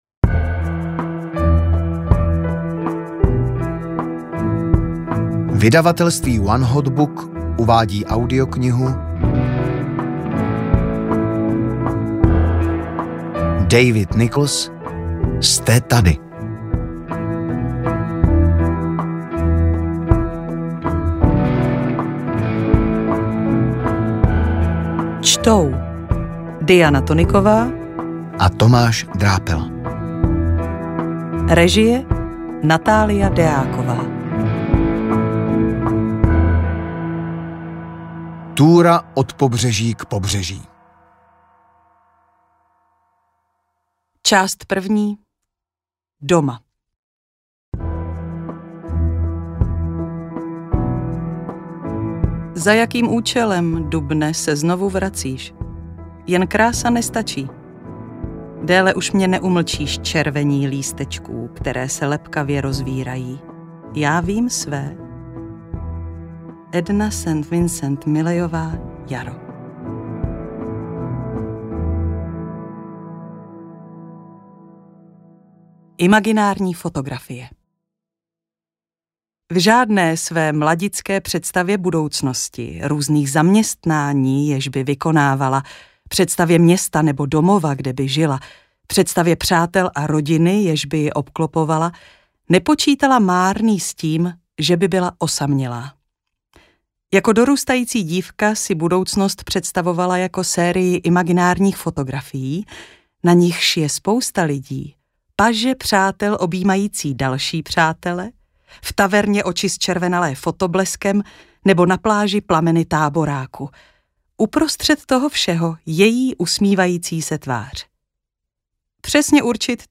AudioKniha ke stažení, 80 x mp3, délka 10 hod. 58 min., velikost 574,0 MB, česky